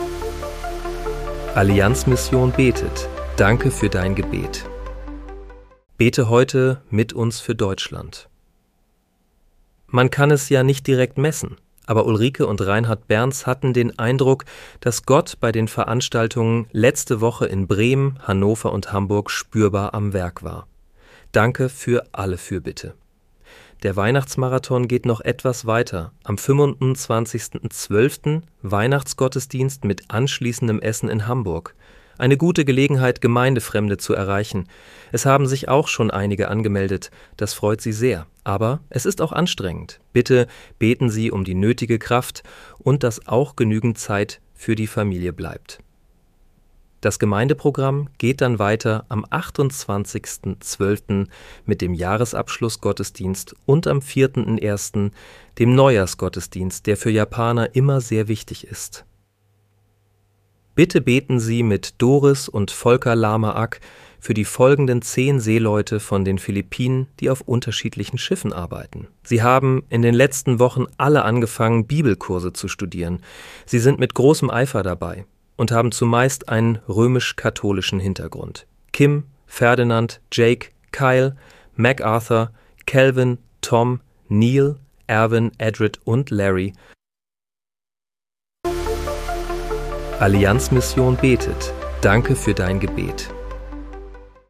Bete am 25. Dezember 2025 mit uns für Deutschland. (KI-generiert